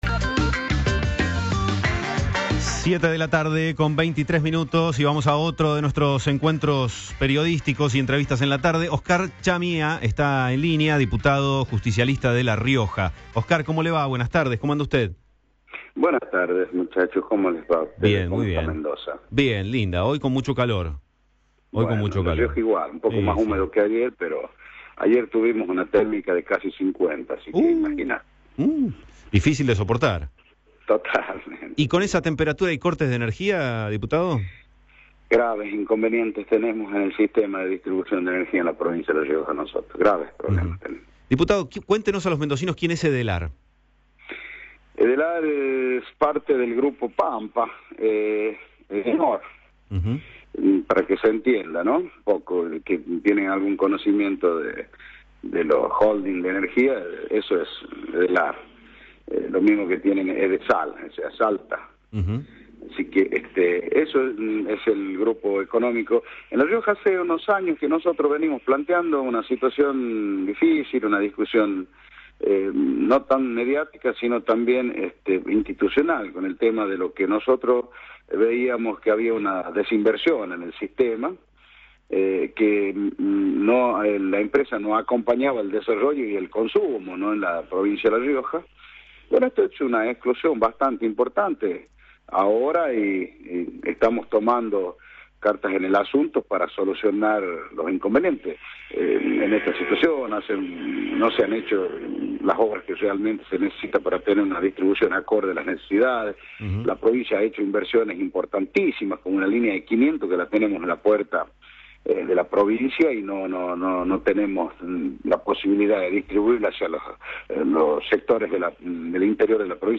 Oscar Chamía, diputado riojano, por MDZ Radio de Mendoza